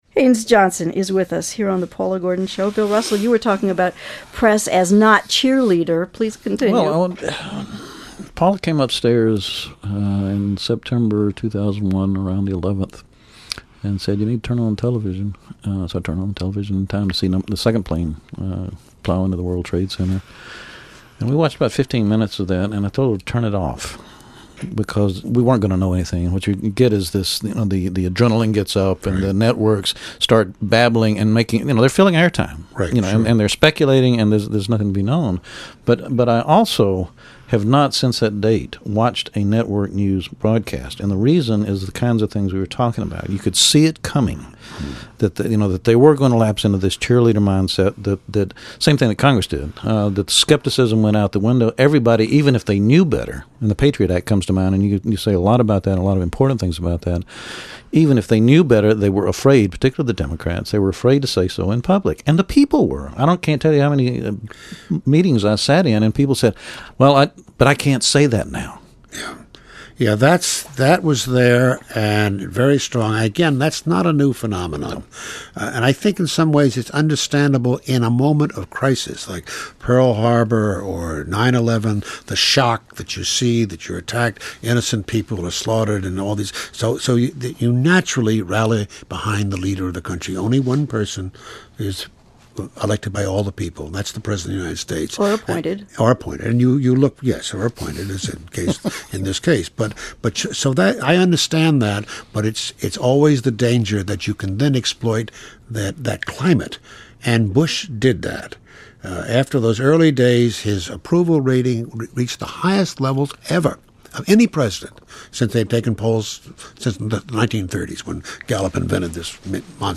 [This Program was recorded October 18, 2005, in Atlanta, Georgia, U.S.]
Conversation 3